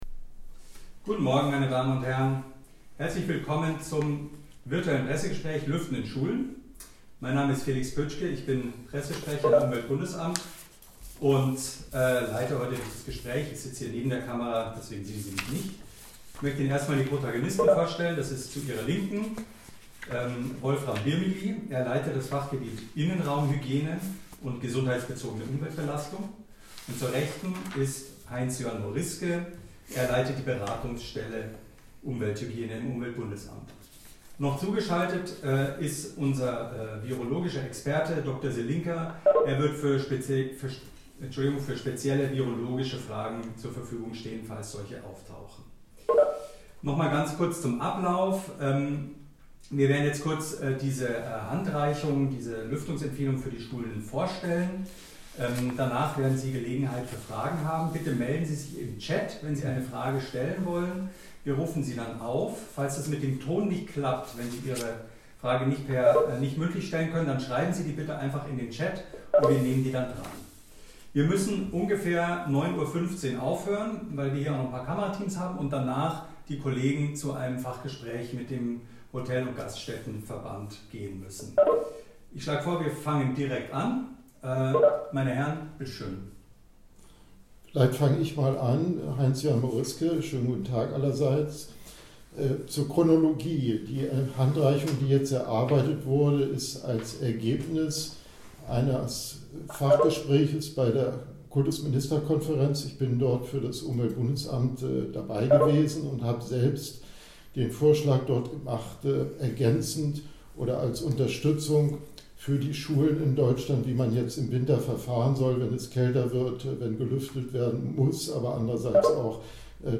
Pressegespräch zum Thema „Lüften in Schulen“